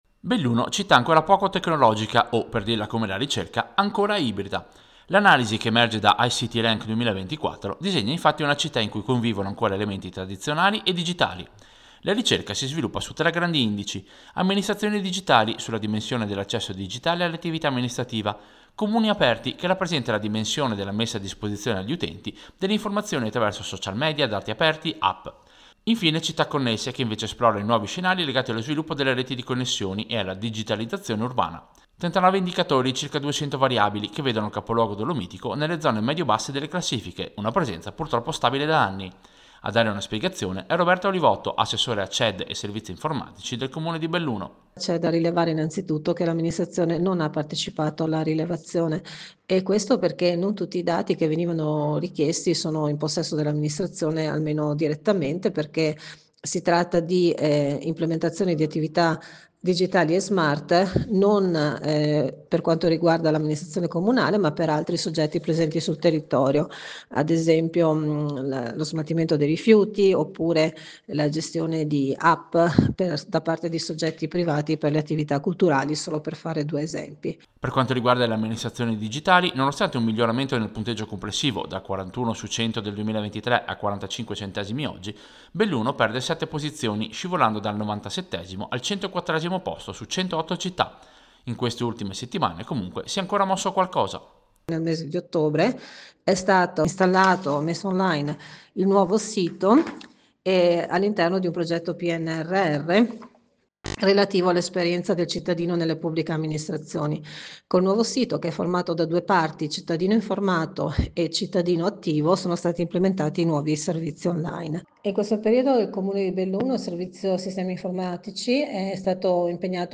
Servizio-Belluno-classifica-digitale.mp3